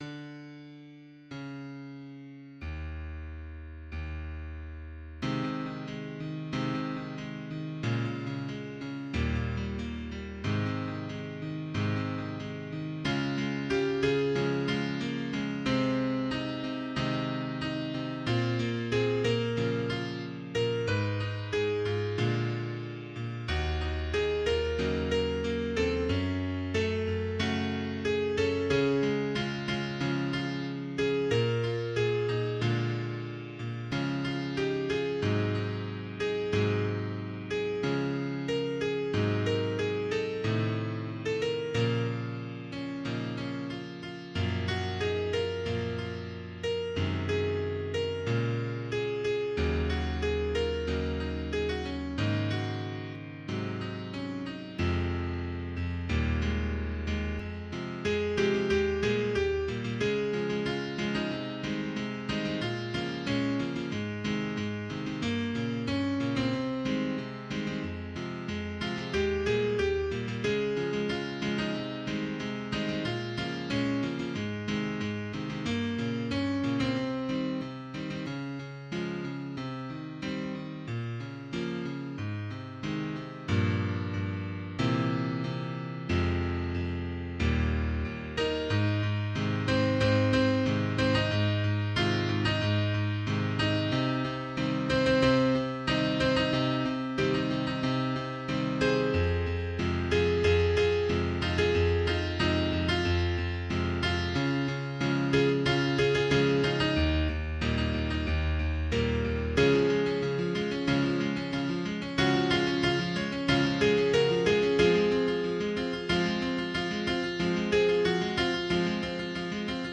高音質作品
ピアノ連弾風